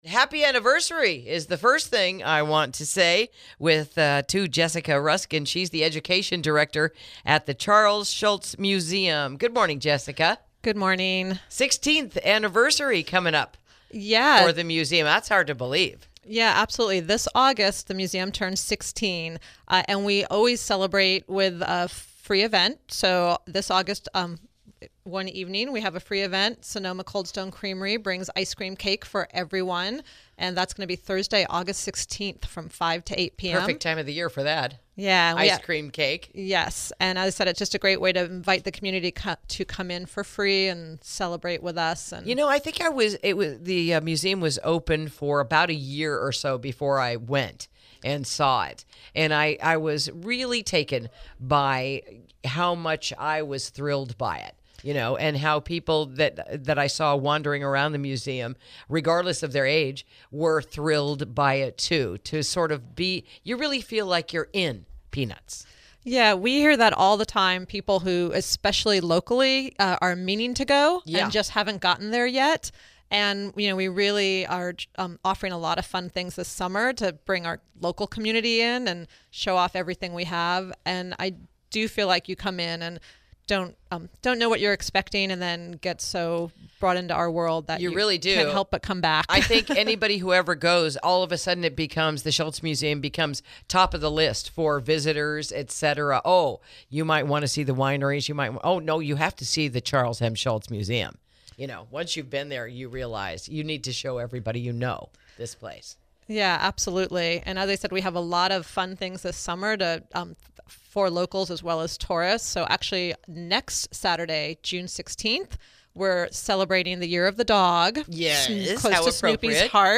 Interview: Animation Classes, Guest Cartoonists and Doghouse Painting All Coming This Summer to the Charles M. Schulz Museum